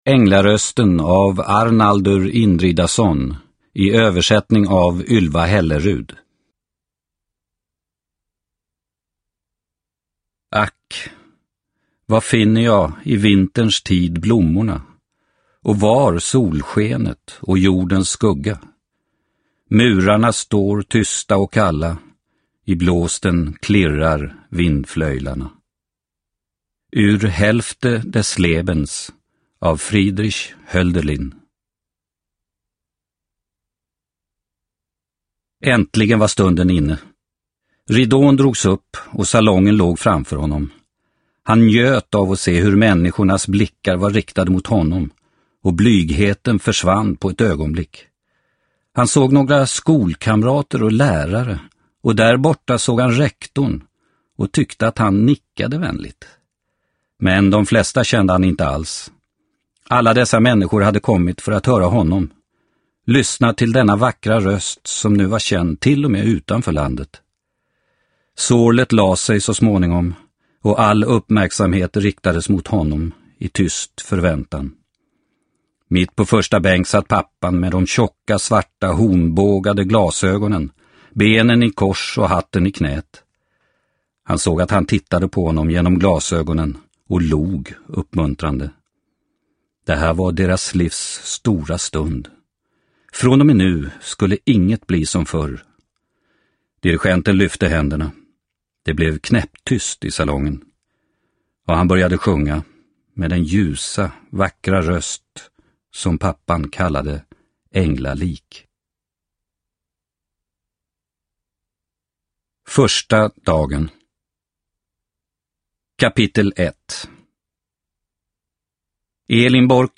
Änglarösten – Ljudbok – Laddas ner